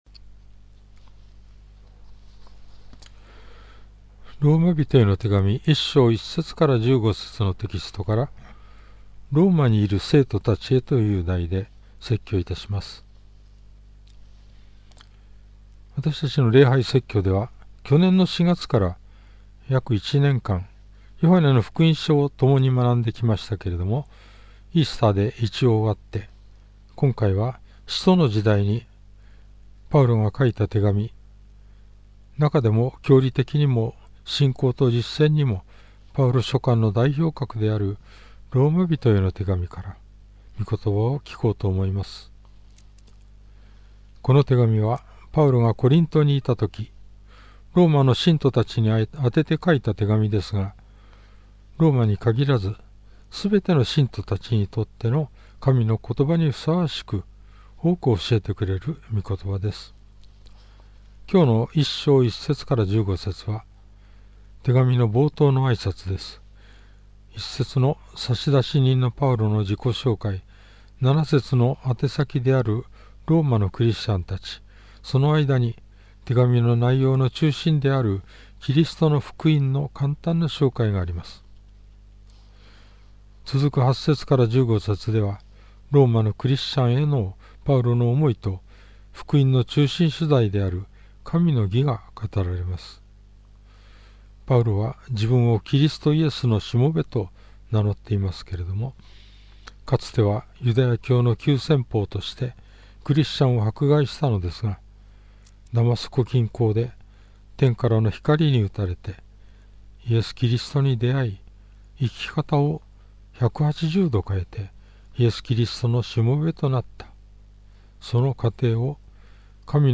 主日礼拝
説教
♪ 事前録音分